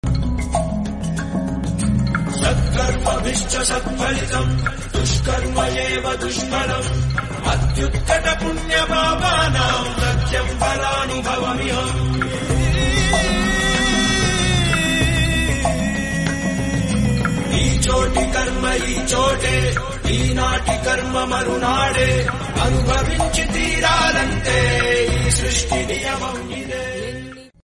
best flute ringtone download | bhakti song ringtone
bhajan ringtone